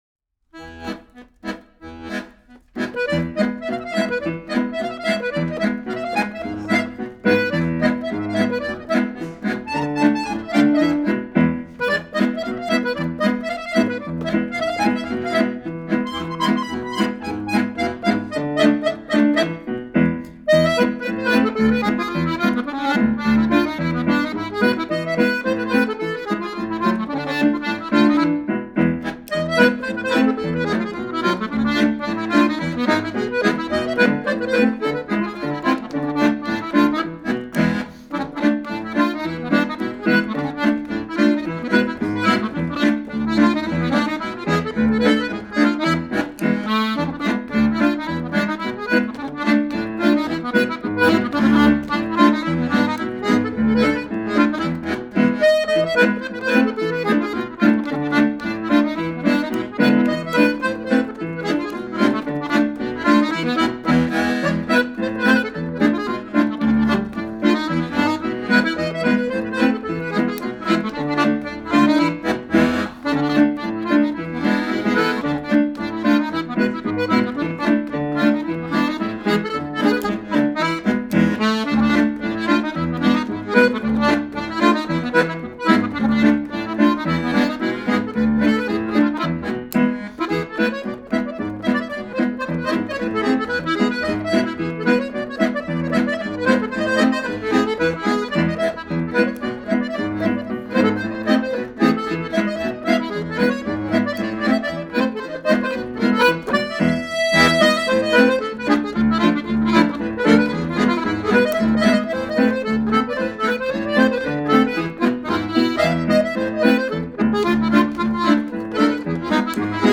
2025 Concert Recordings  - Wisdom House, Litchfield, CT